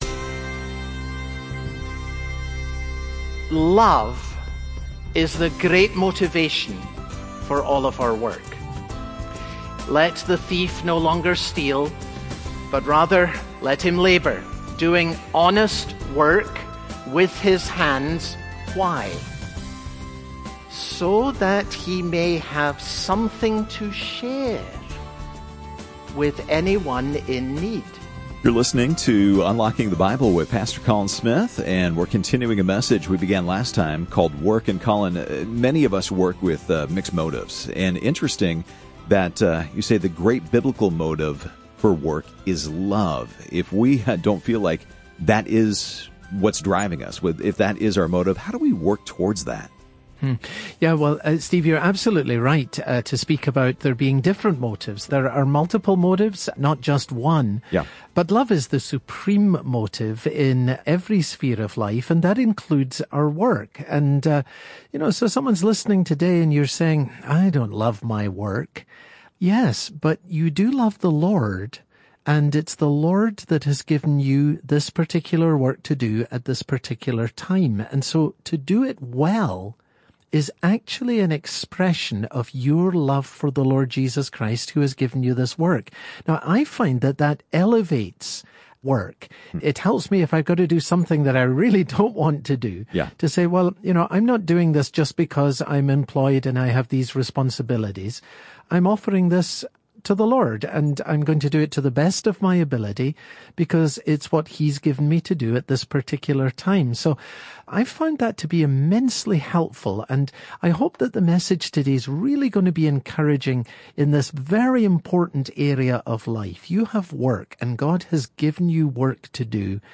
Part 2 Proverbs Broadcast Details Date Sep 15